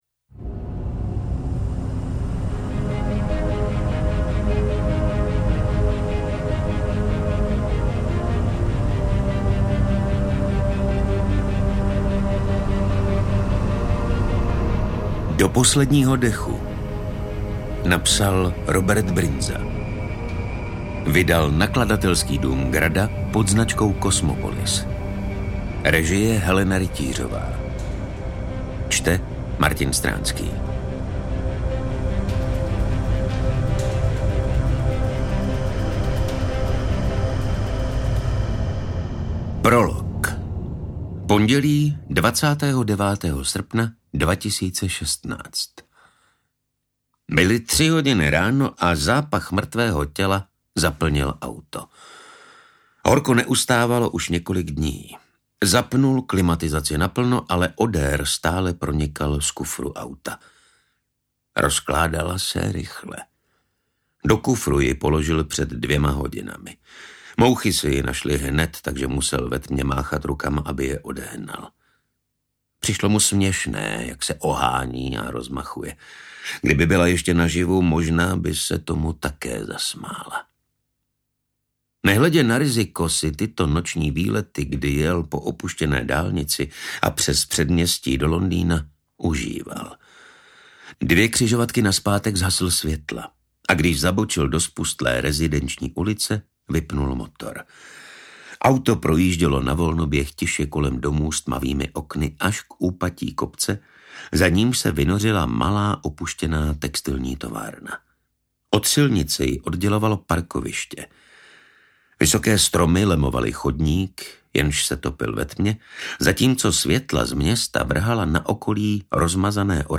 Do posledního dechu audiokniha
Ukázka z knihy